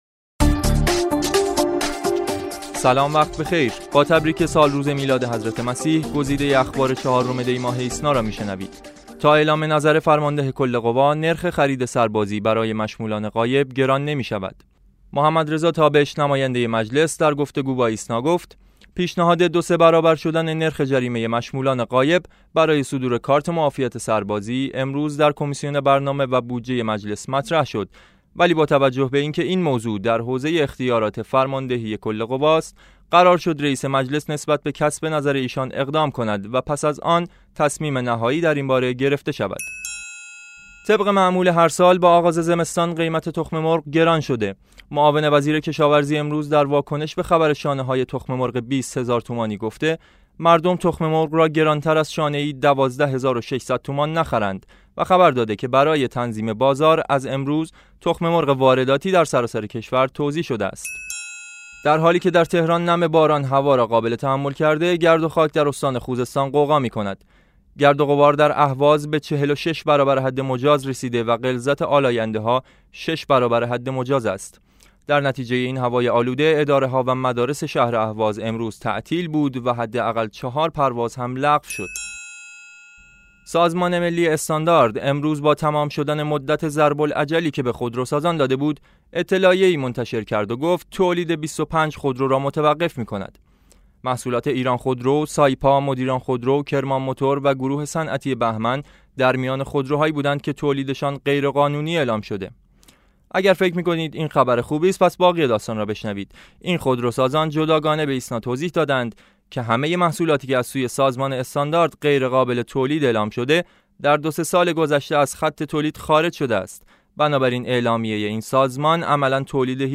صوت / بسته خبری ۴ دی ۹۶